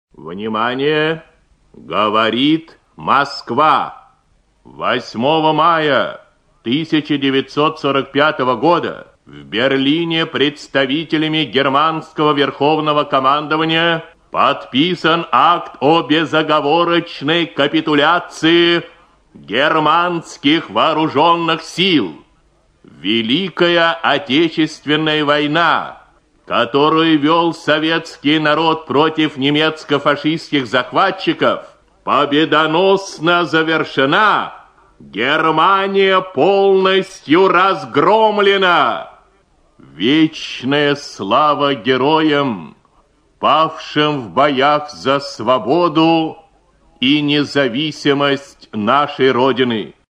Приказ Верховного главнокомандующего 9 мая 1945 г. Читает диктор Ю.Б. Левитан.